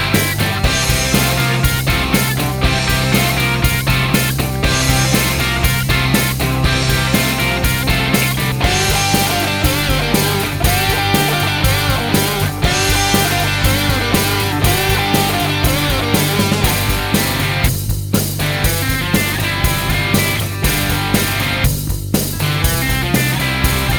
no Backing Vocals Rock 2:46 Buy £1.50